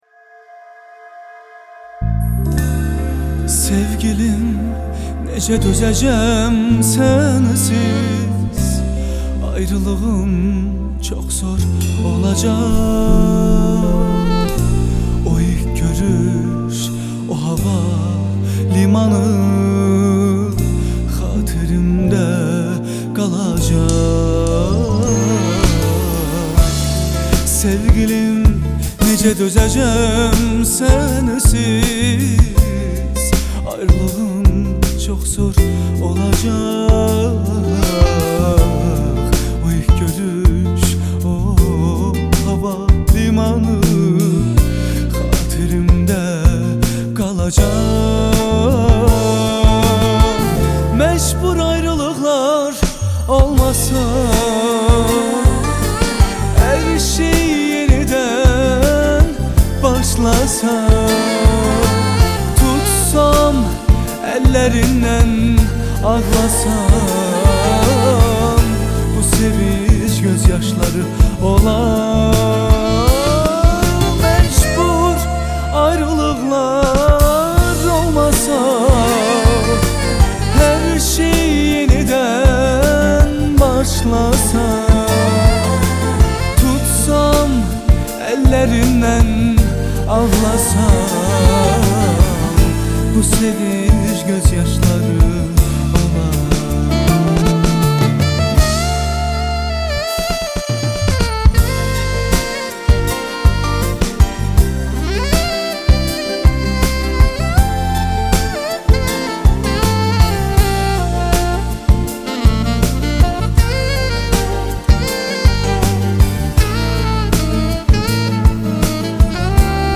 Грустные